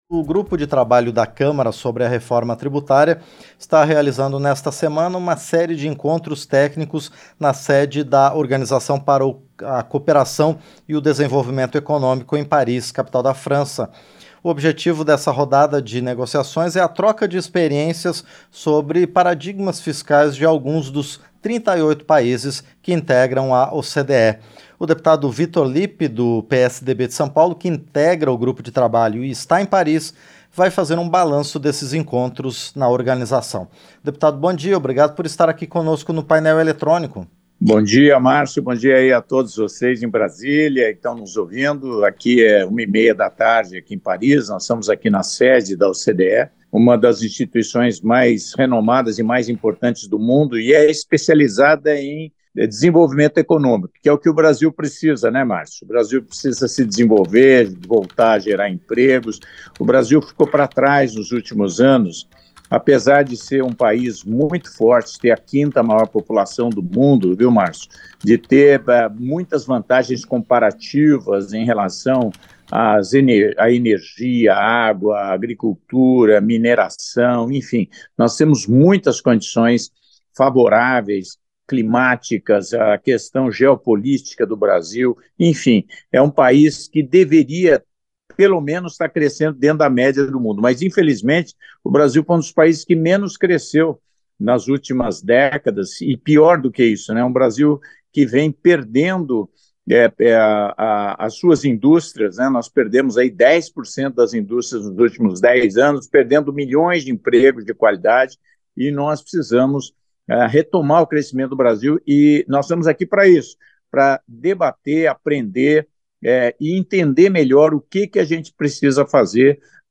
Entrevista - Dep. Vitor Lippi (PSDB-SP)